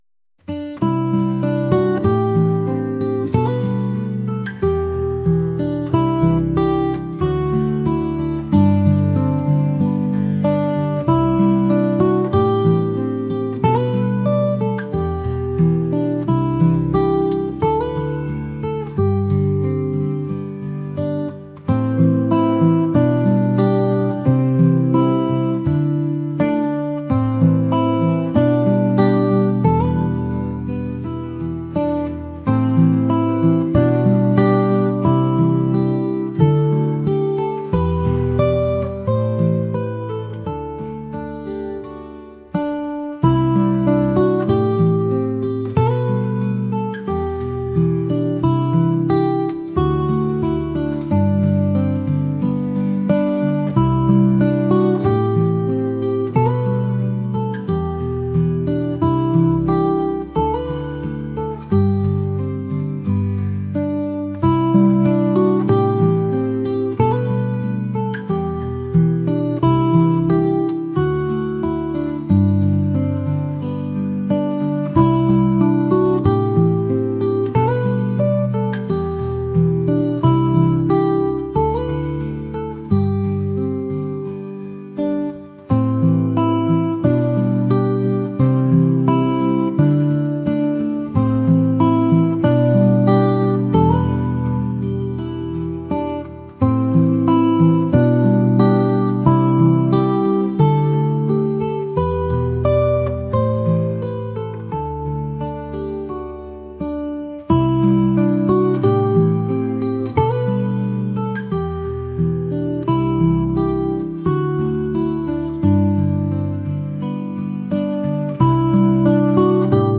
acoustic | ambient | folk